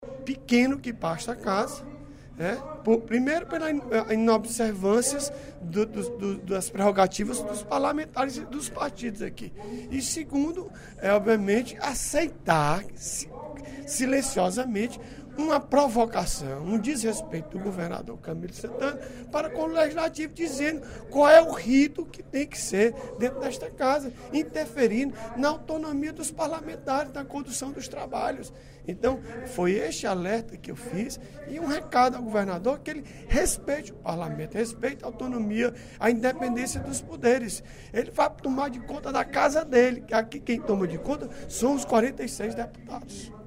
O deputado Odilon Aguiar (PMB) criticou, no primeiro expediente da sessão plenária da Assembleia Legislativa desta terça-feira (11/07), demora em decisões que precisam ser tomadas pela Casa.